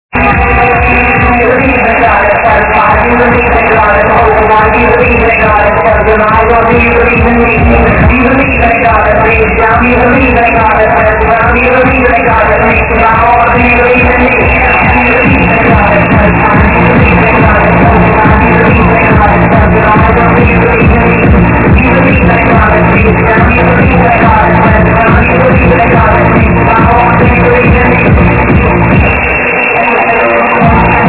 Can u know this vocal track?